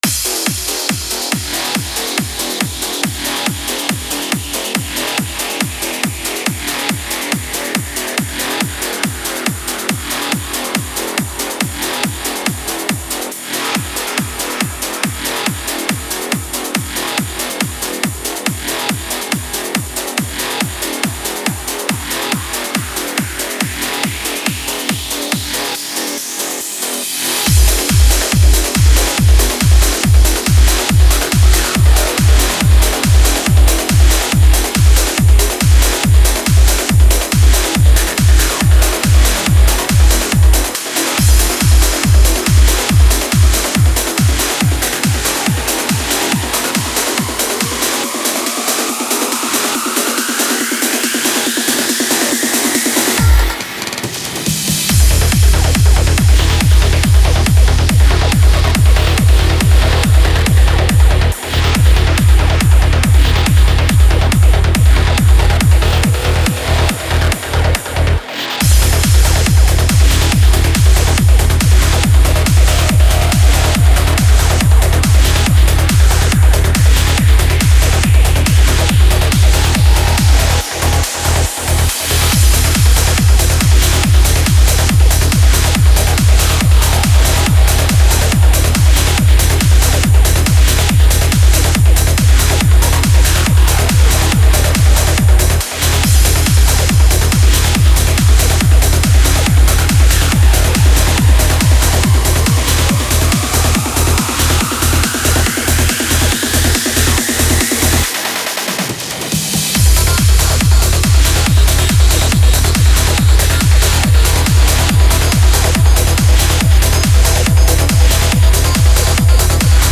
darker variant of Trance.
contains energetic bass lines, powerful sub bass,